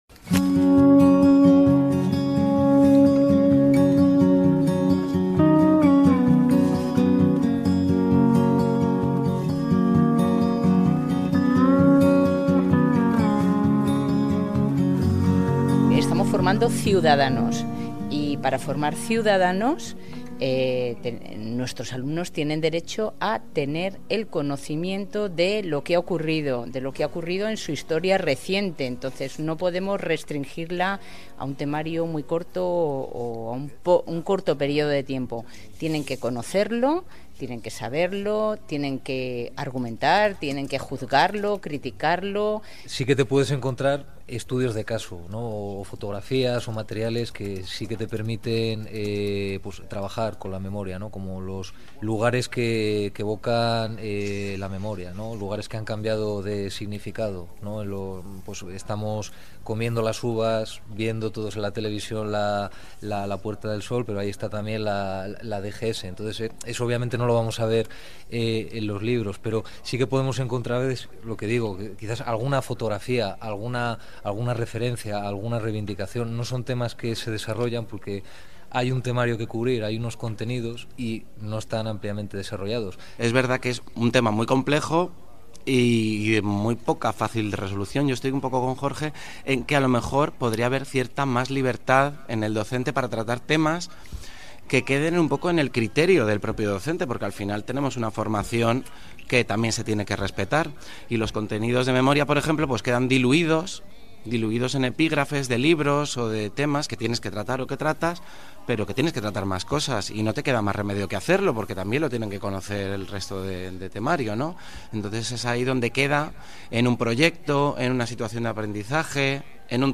por Javier del Pino "a vivir que son dos días"